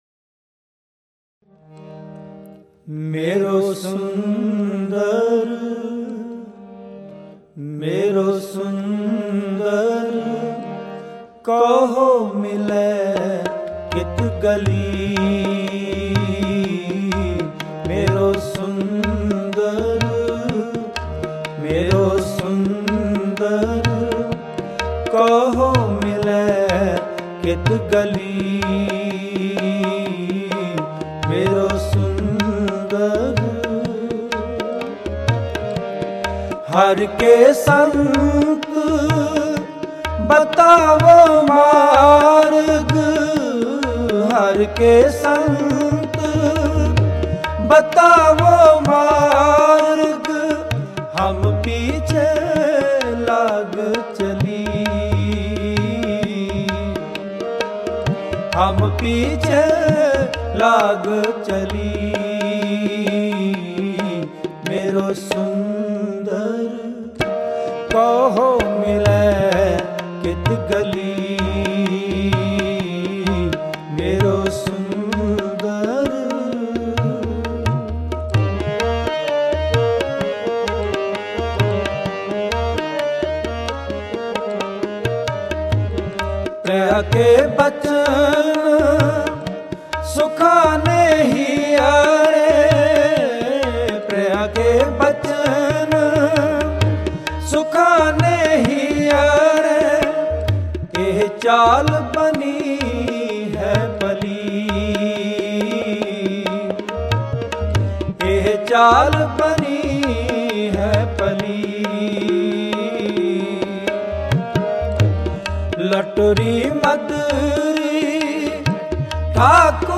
Genre: Shabad Gurbani Kirtan